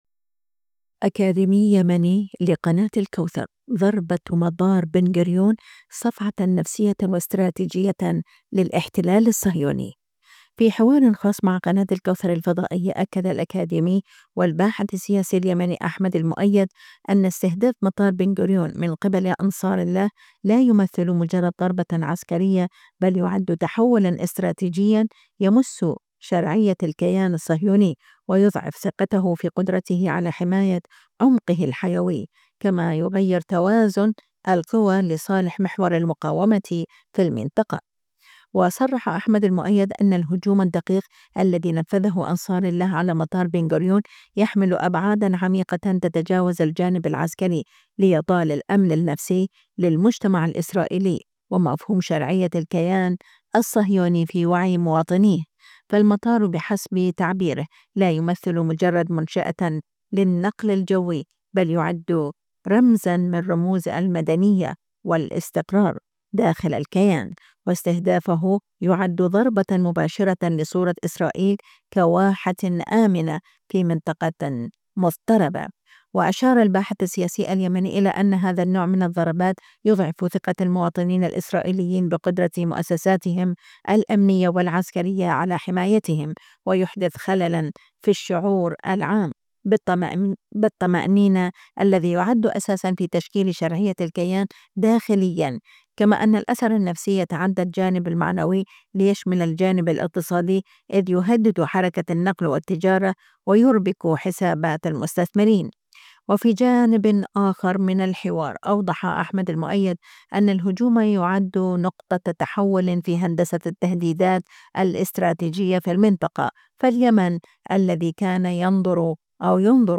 الكوثر - لقاء خاص